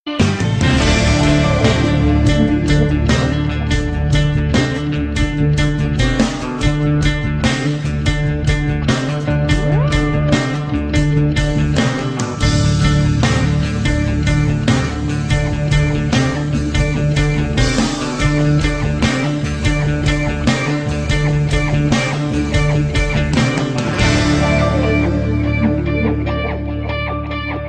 نغمة ميكس تركي رائع
World
tunes , turkish , albanian , shqip , balkans ,